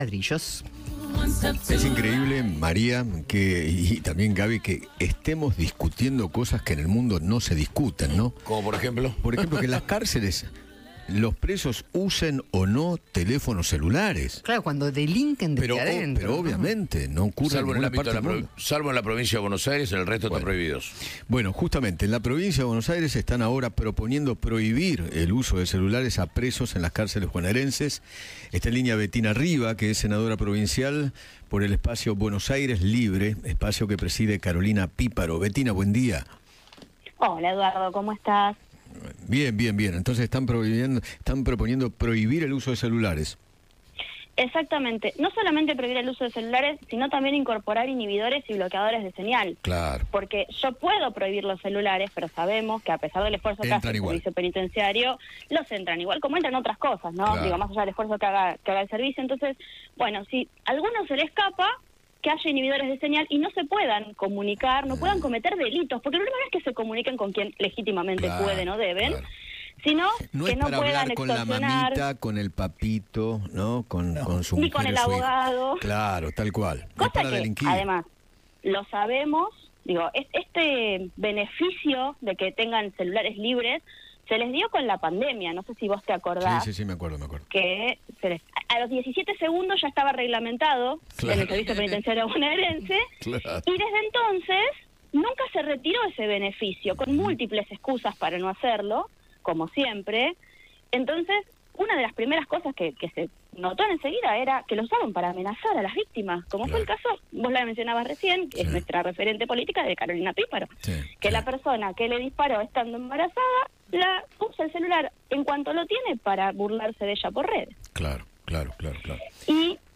Betina Riva, senadora de la Provincia de Buenos Aires, habló con Eduardo Feinmann sobre el proyecto de ley que propuso para prohibir el uso de celulares en las cárceles e incorporar inhibidores y bloqueadores de señal.